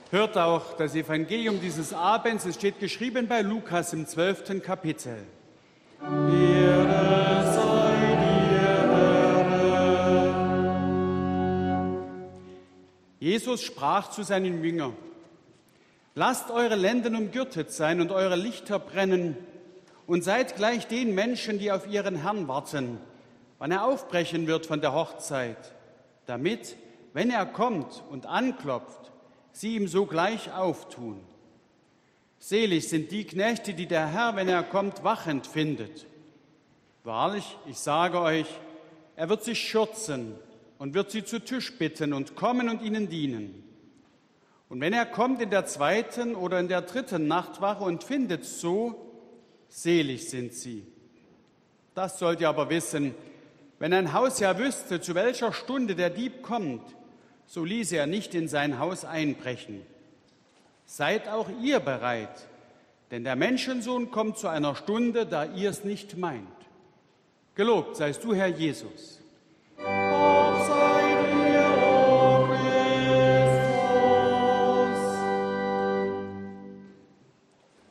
Audiomitschnitt unseres Gottesdienstes vom Altjahresabend 2022.